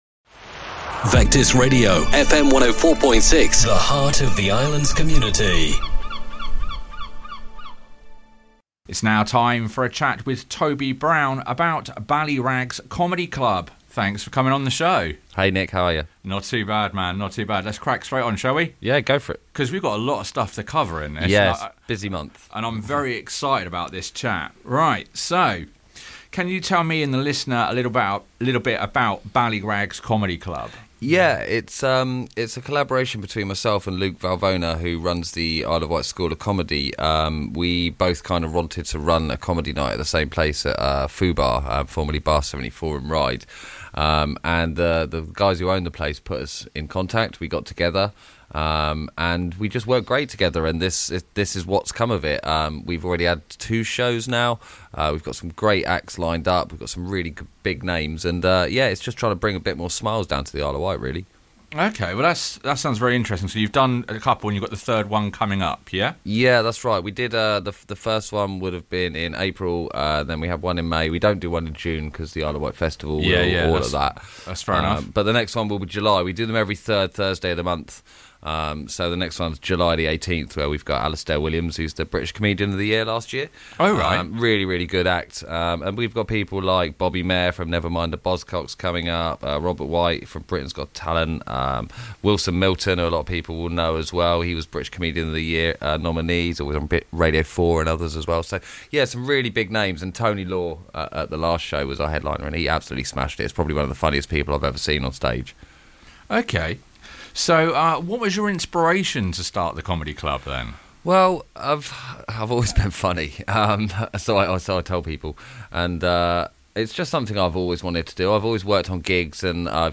If you missed the original broadcast here is the podcasted version of the chat